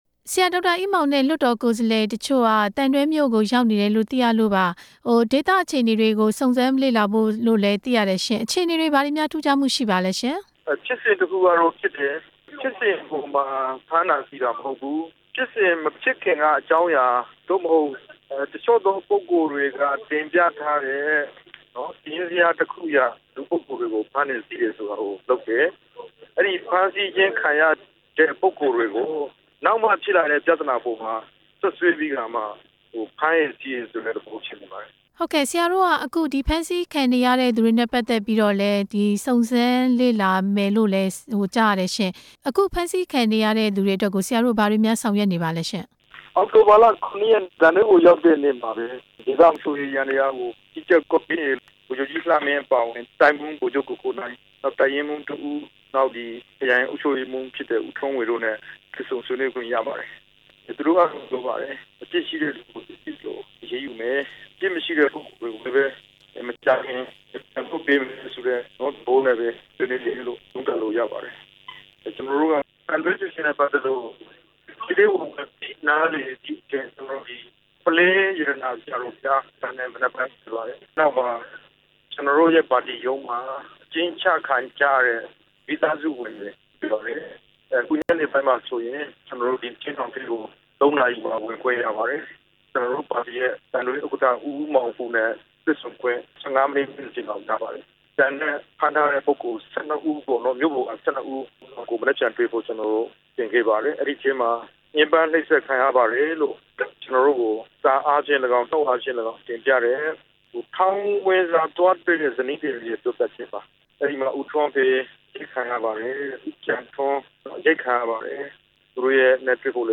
ဒေါက်တာအေးမောင်ကို ဆက်သွယ်မေးမြန်းချက်